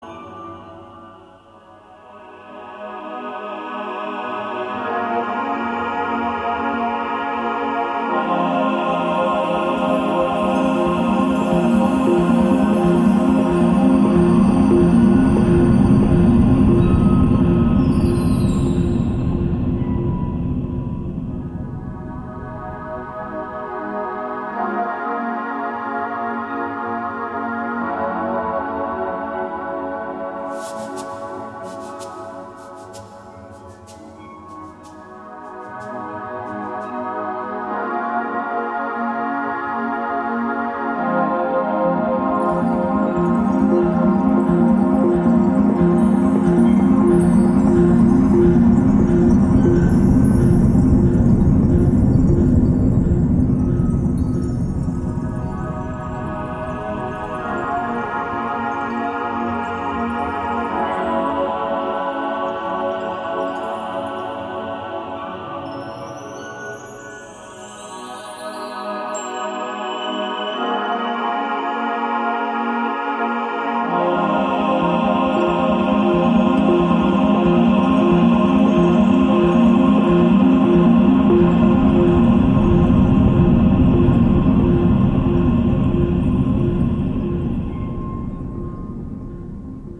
Electronix Soundtrack Ambient